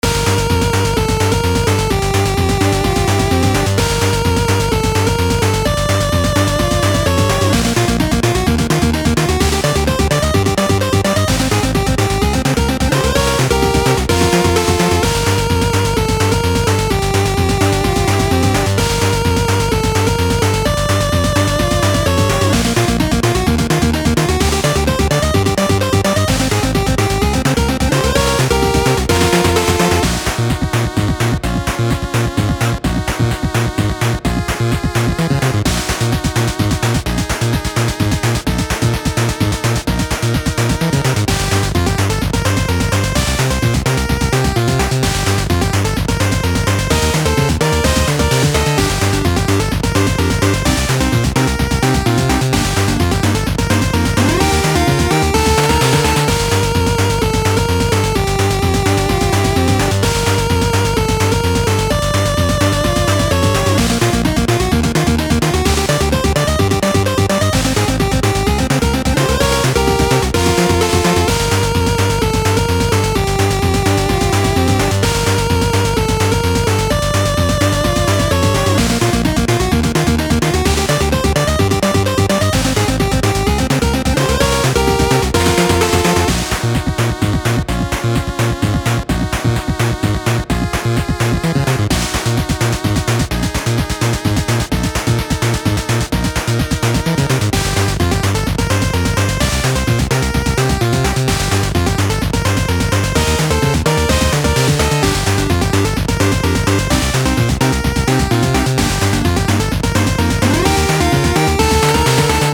8-bit - Perilous Dungeon
8-bit music
perfect for any action games (platformer, RPG, shooter, ...)The track loops seamlessly